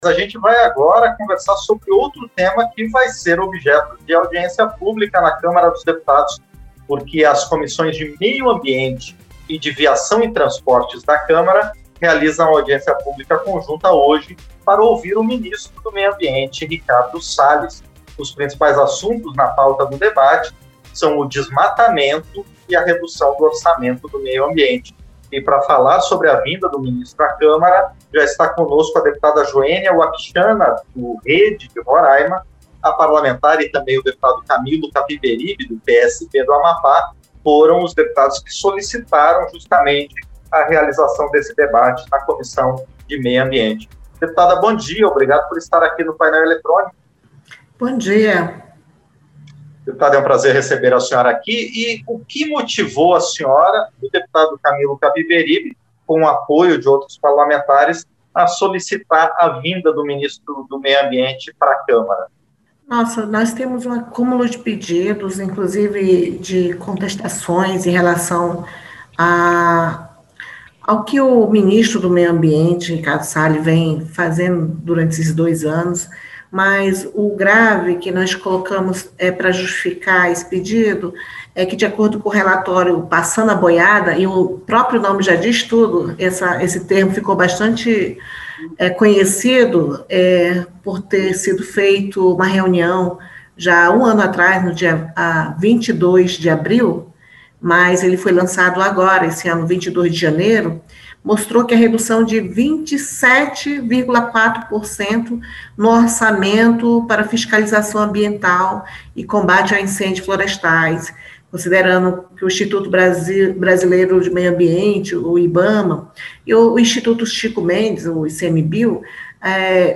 Entrevista - Dep. Joenia Wapichana (Rede-RR)